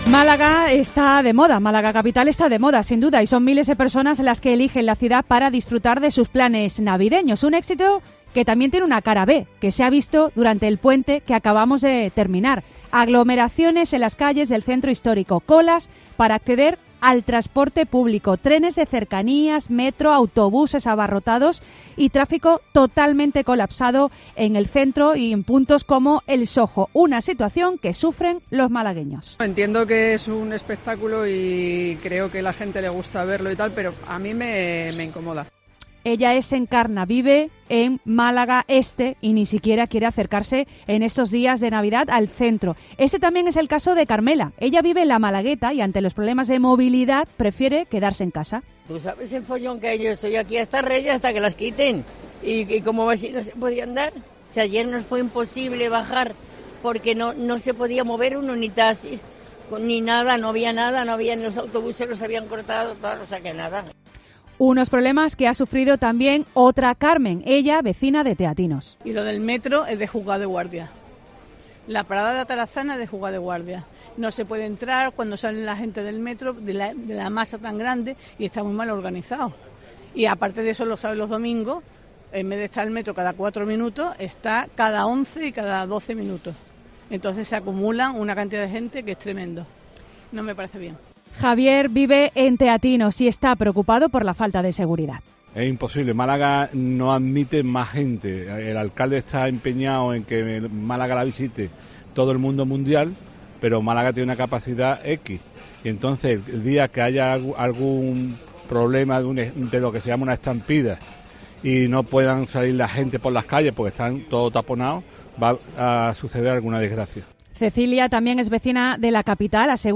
En COPE Málaga hemos salido a la calle para preguntarle a los malagueños cómo se encuentran ante esta situación